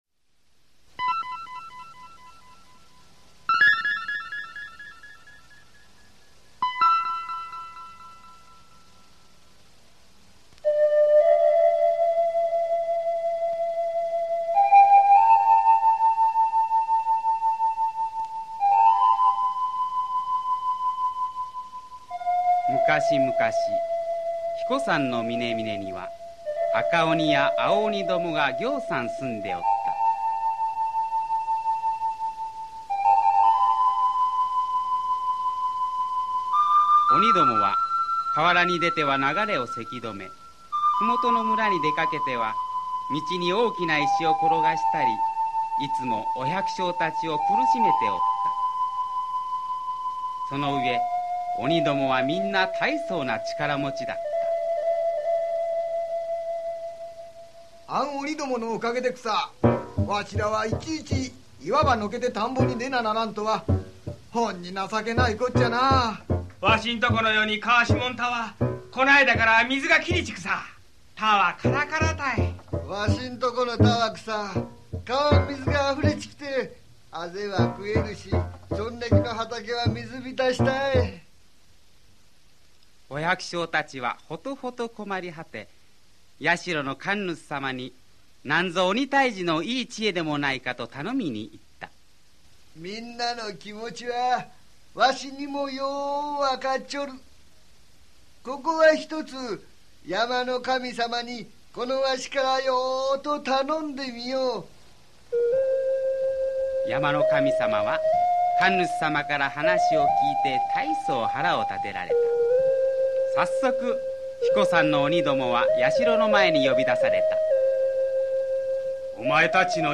[オーディオブック] 英彦山の鬼杉